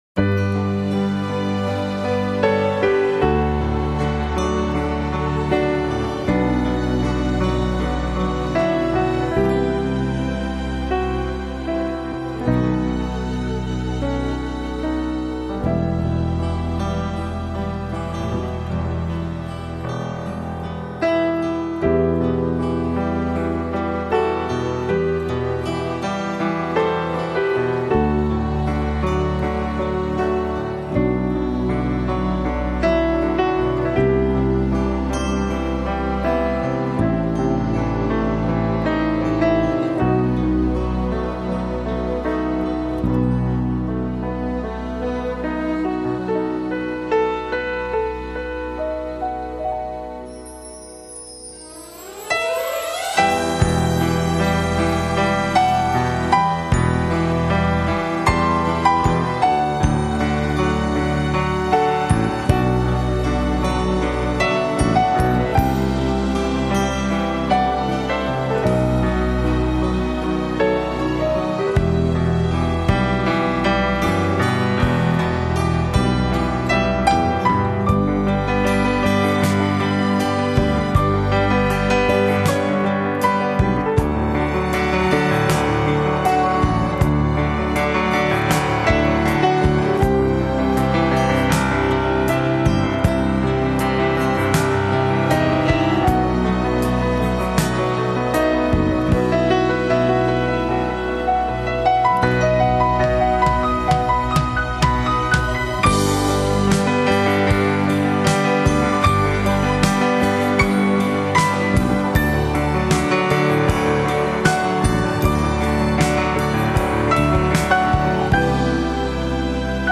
钢琴纯音乐
加上优美动听的旋律，梦幻般的细致情调，浪漫清雅的色彩，精湛超卓的演奏技巧，实为不可缺少的音乐，音响收藏品 。